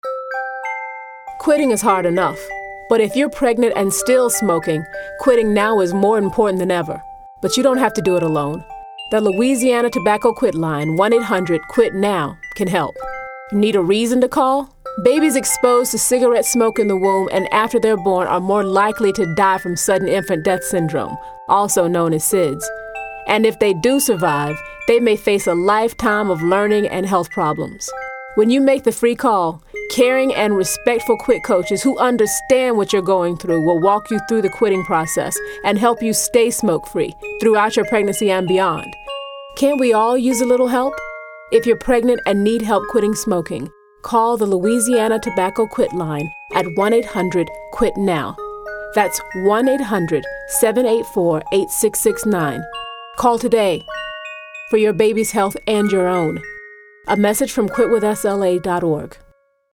radio ad itself.